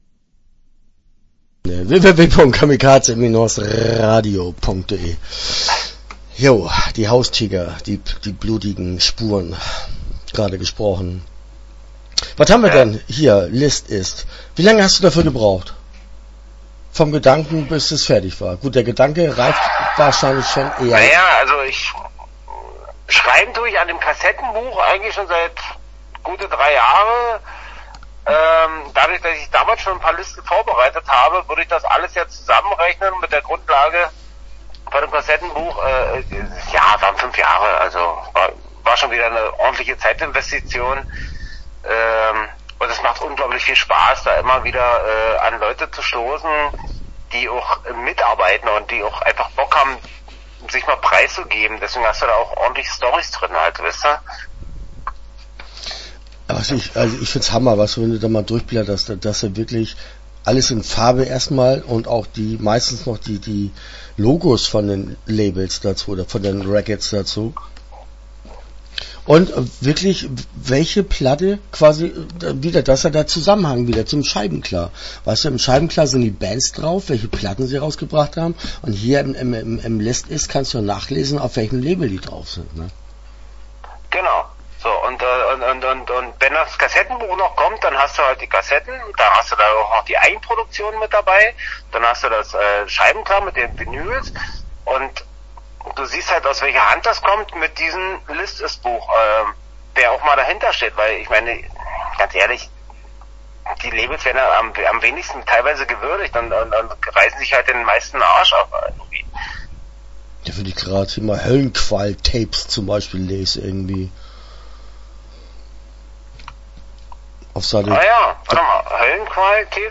LIST-IST - Interview Teil 1 (8:22)